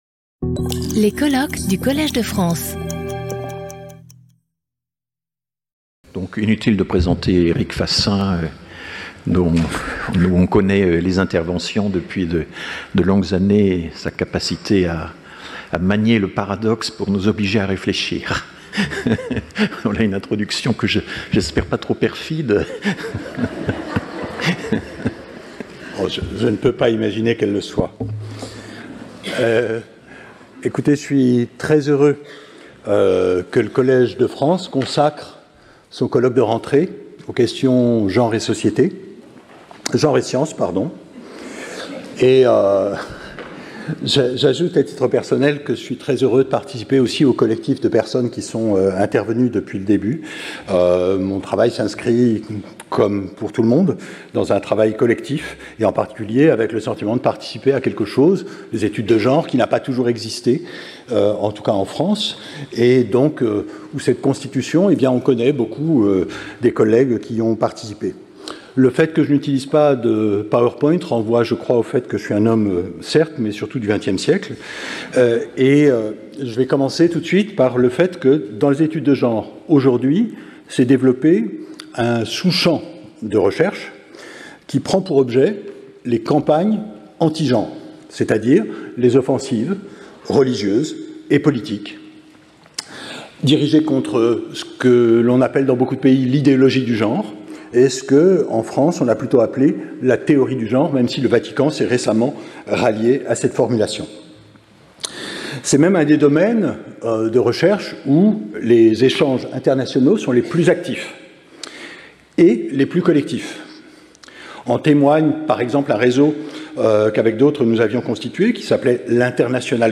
Sauter le player vidéo Youtube Écouter l'audio Télécharger l'audio Lecture audio Séance animée par François Héran. Chaque communication de 30 minutes est suivie de 10 minutes de discussion.